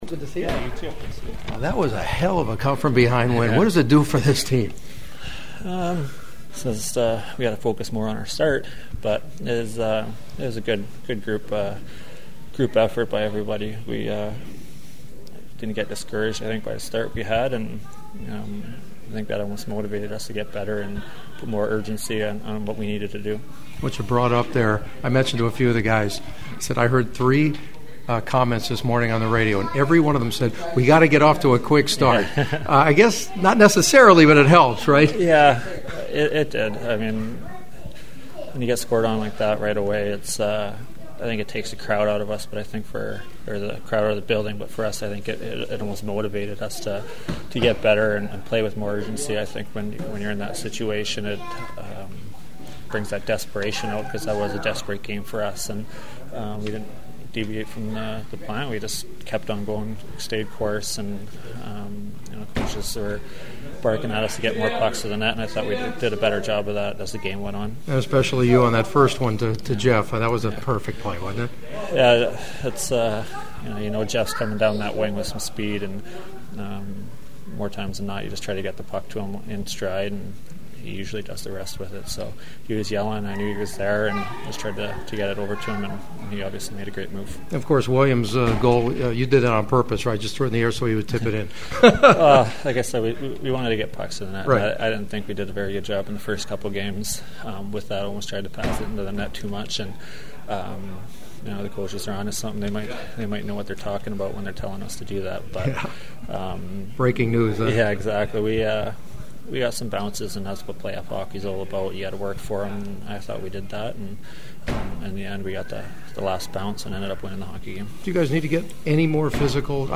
The following are my postgame chats from the locker room and they were all cautiously stoked about their chances during the rest of this series…
Kings center Mike Richards who got 2 big helpers and continues to play as steady as they come: